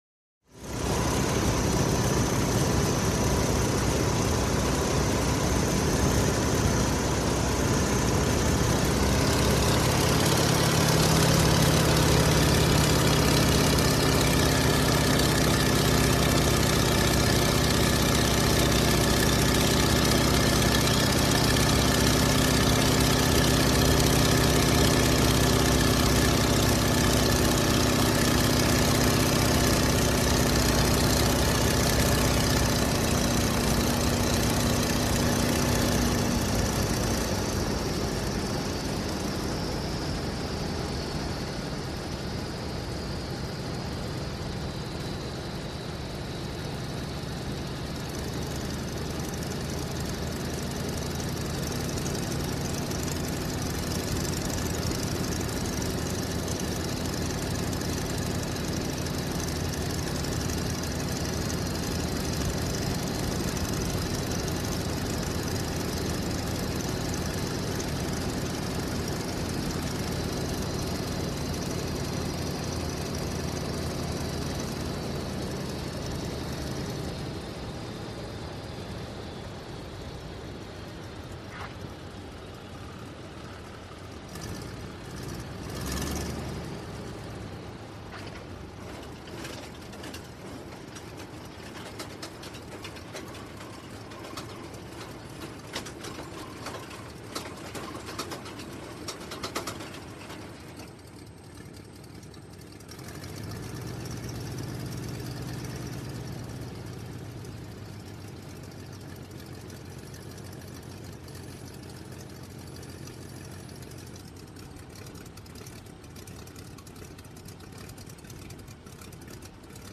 Реалистичные записи передают гул двигателей, скрип тормозов и другие детали, создавая эффект присутствия на взлетно-посадочной полосе.
Шум посадки кукурузника